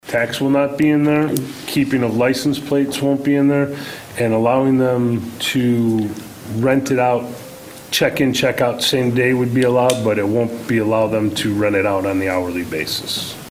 An ordinance to amend the short term rental business regulations in the Village of Manteno was passed at Monday night’s board meeting. Trustee Joel Gesky says it does not include a new tax.